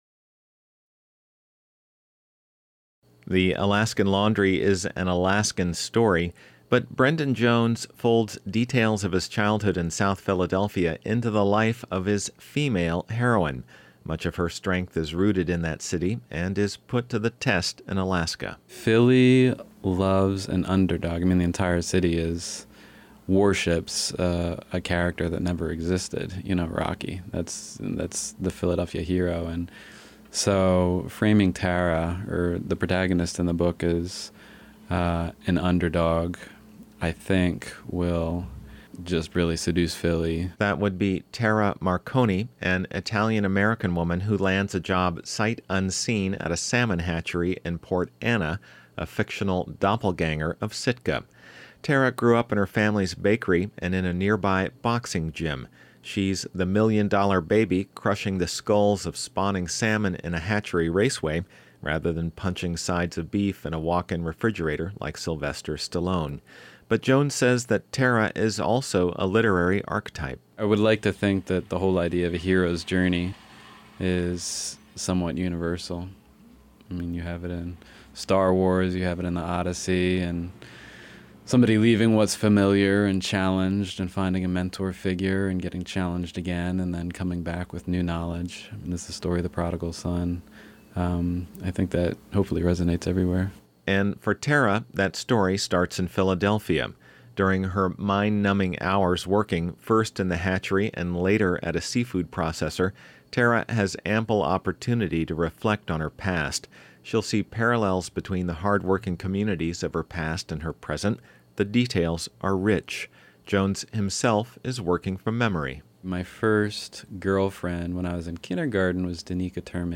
Maybe she’ll hear this Raven broadcast and she’ll call in.